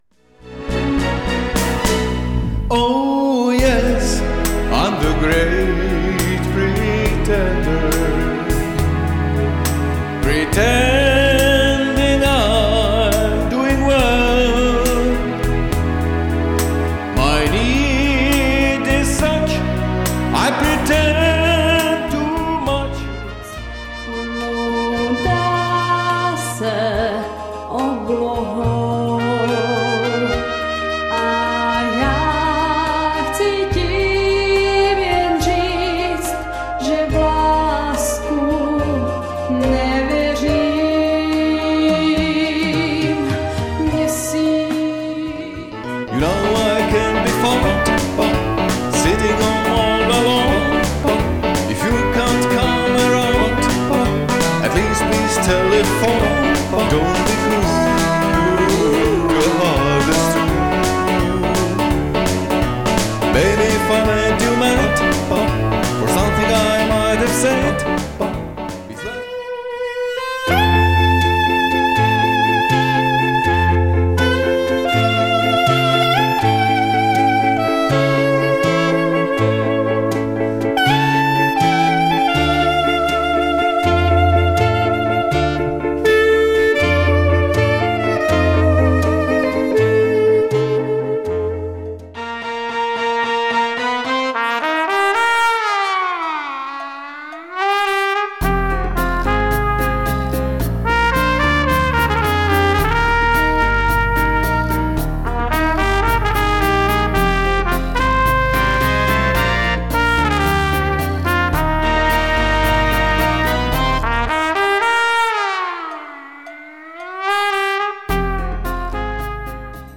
Živá hudba na svatby, plesy, firemní večírky a oslavy.
Poslechněte si, jak hrajeme naživo.